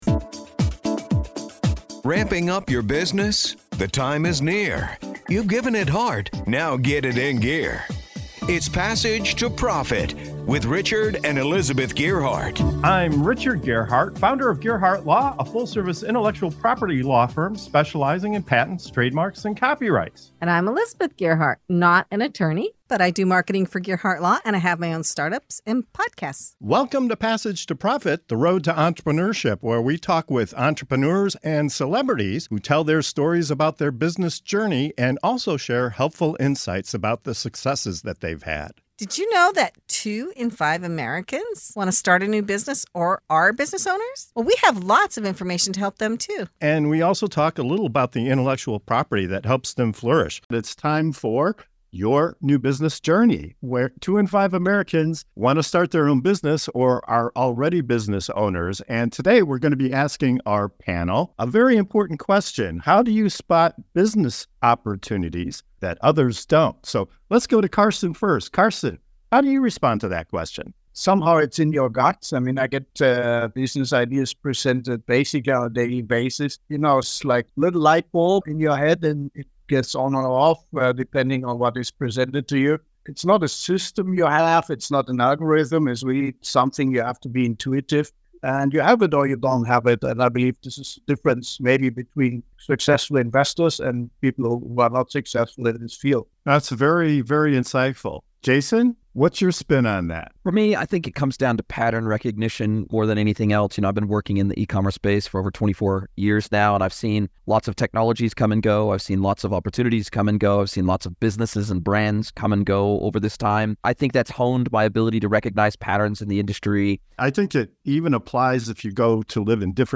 In this segment of "Your New Business Journey" on Passage to Profit Show, we dive into the art of spotting business opportunities that others might miss. Our panel shares diverse perspectives, from trusting your gut and recognizing industry patterns to the power of curiosity and asking the right questions.